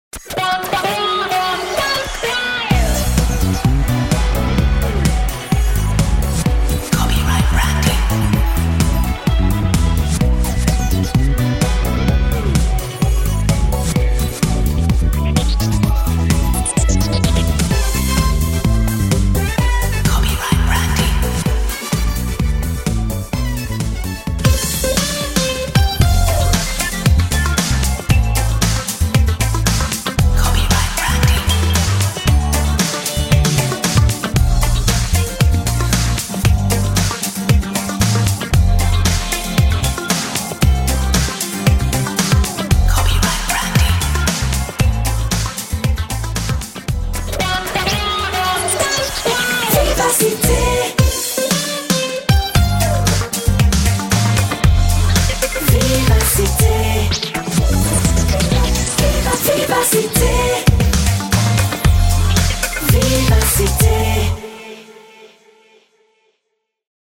Feel the subtle birthday nod